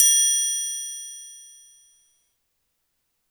• Ride Cymbal F# Key 03.wav
Royality free ride cymbal sound sample tuned to the F# note. Loudest frequency: 7168Hz
ride-cymbal-f-sharp-key-03-OMb.wav